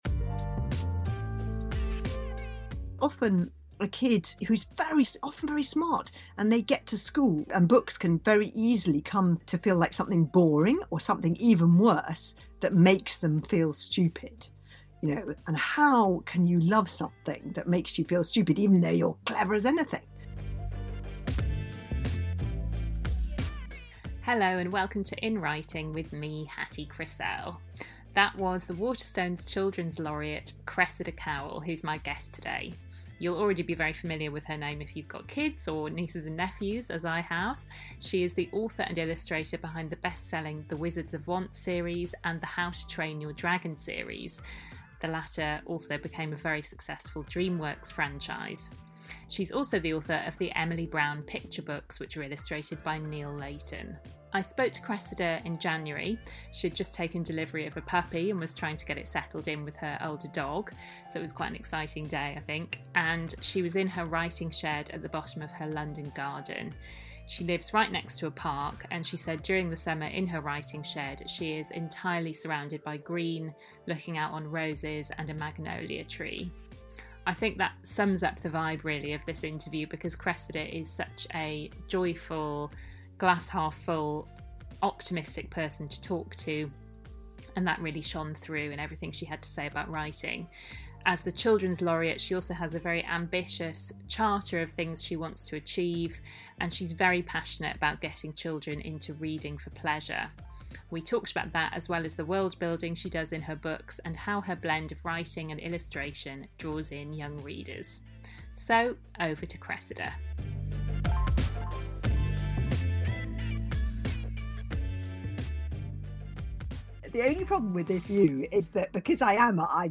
The writer and illustrator Cressida Cowell joins me this week, with a backdrop of birdsong from outside her writing shed.